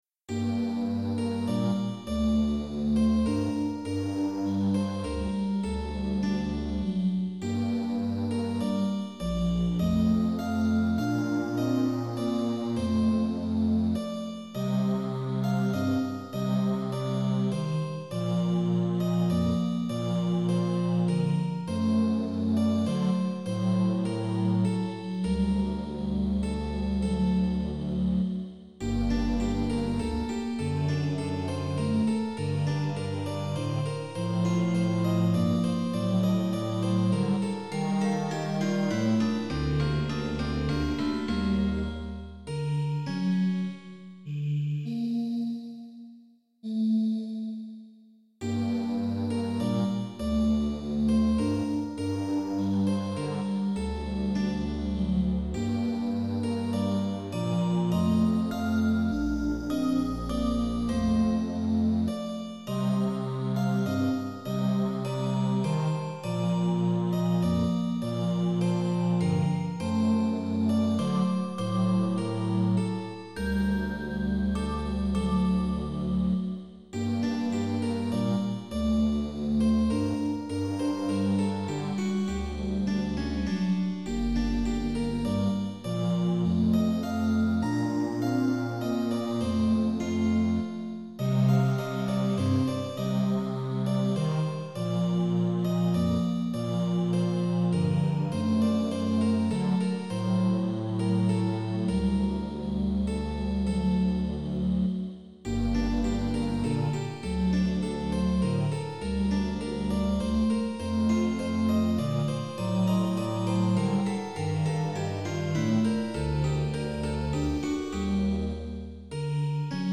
Most of these pieces I performed myself in public concerts.
The sound quality is slightly better on the CD.